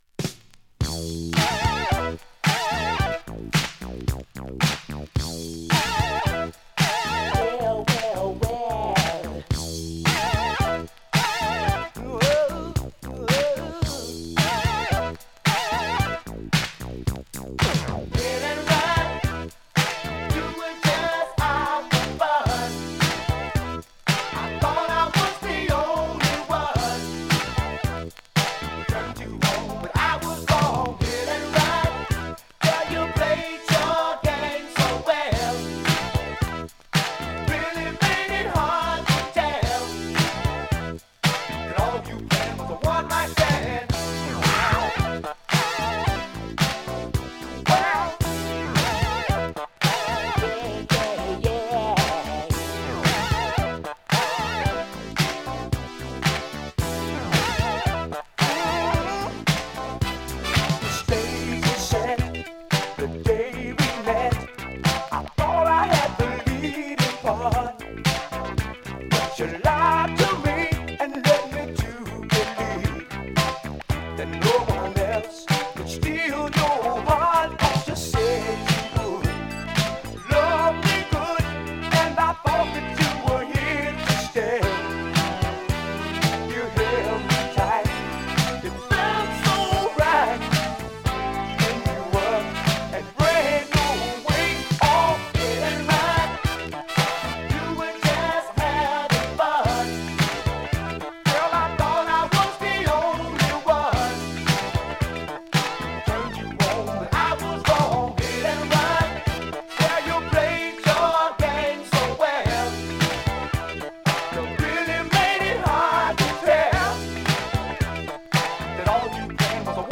現物の試聴（両面すべて録音時間８分）できます。
定番のエレクトリック・ファンク・ブギー